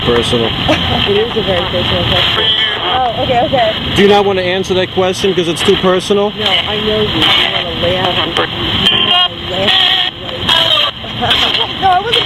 For a good stretch the Ghost Box is dead silent except for white noise.
Because a split second later  you hear a singing voice saying (at 9 seconds)....."do not....tan.....hello!"